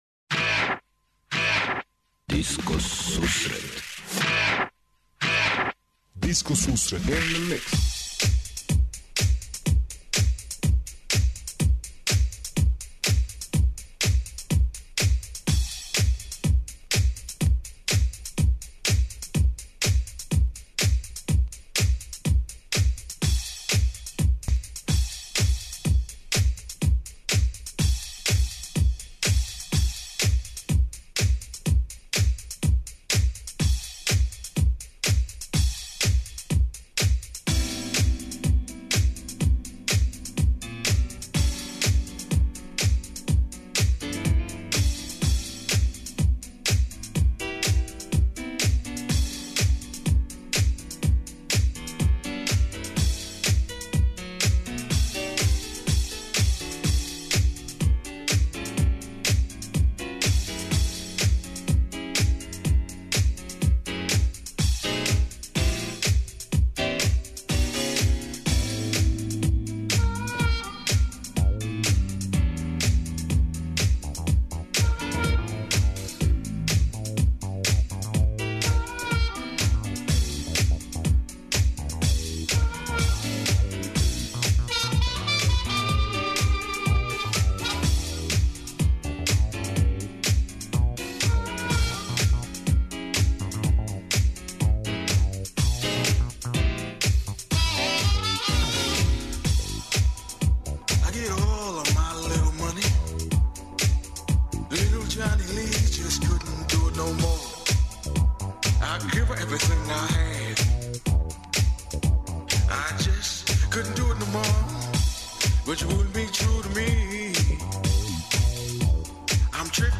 најновијој и оригиналној диско музици у широком смислу
фанк, соул, РнБ, итало-диско, денс, поп.